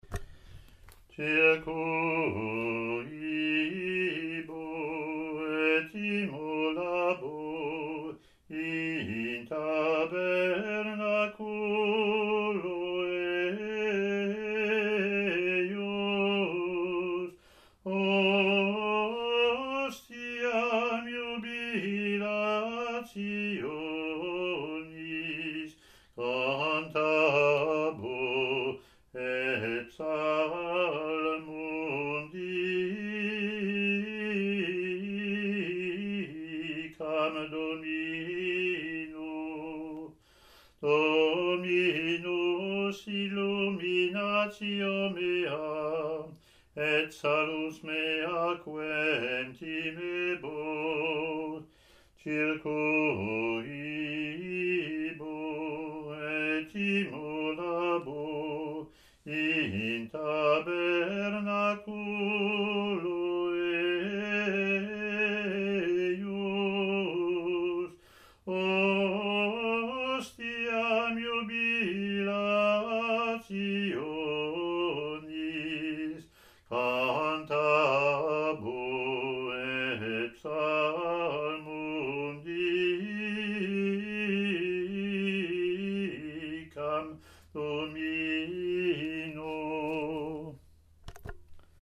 Year B Latin antiphon + verses, Year C Latin antiphon + verses)